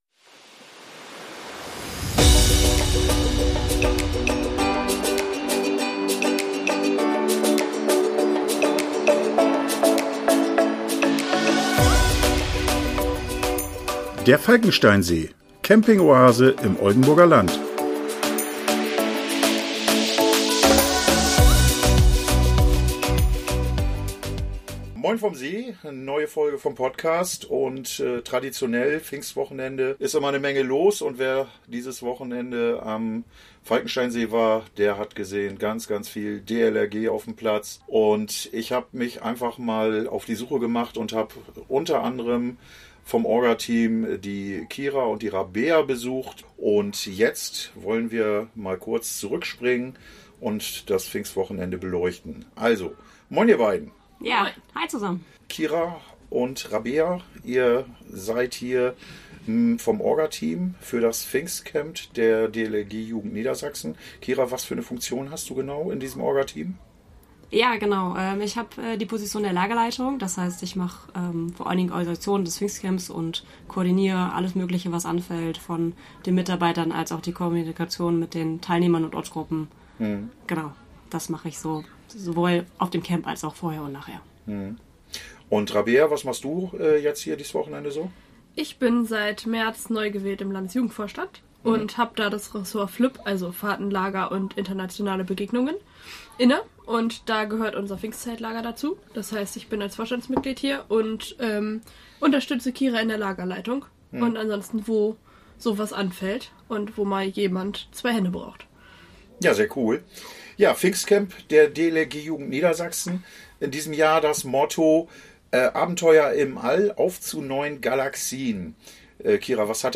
Wir erfahren etwas über die "Galaxie" Falkensteinsee, den organisatorischen und logistischen Aufwand, sowie über die DLRG-Familie. Ein interessantes Gespräch, nicht nur für DLRG-Angehörige.